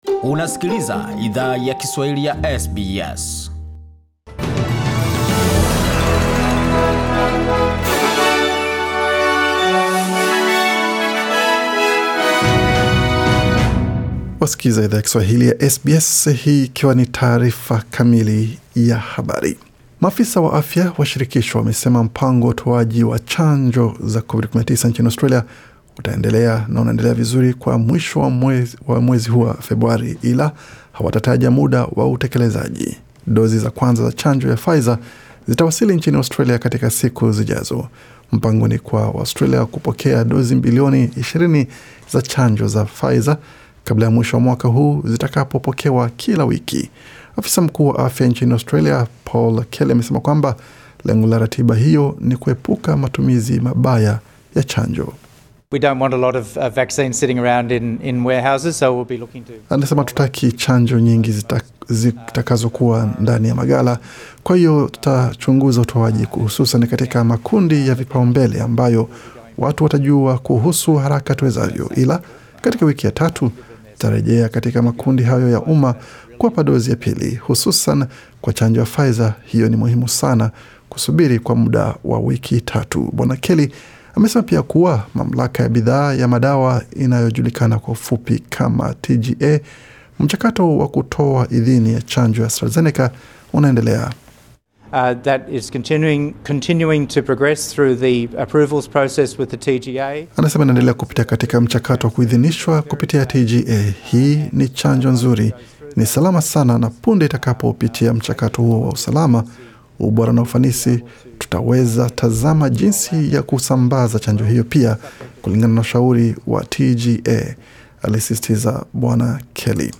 Taarifa ya habari 9 Februari 2021